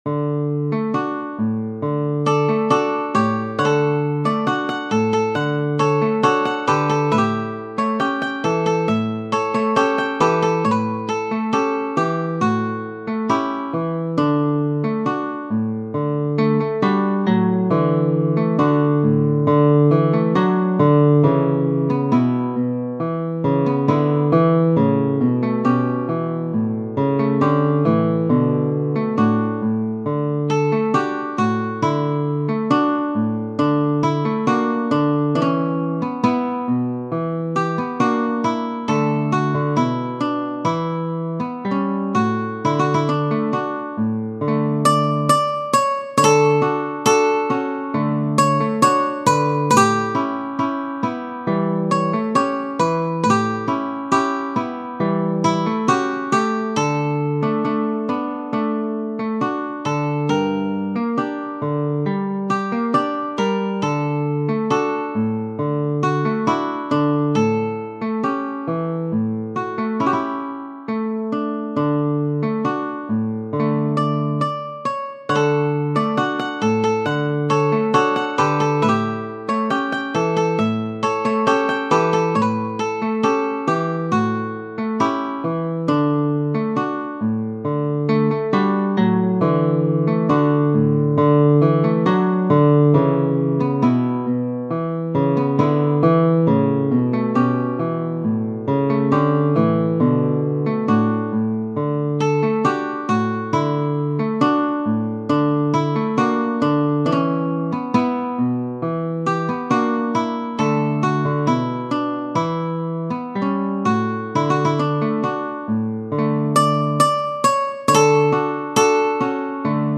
Di Capua, E. Genere: Napoletane Testo di G. Capurro, musica di E. Di Capua.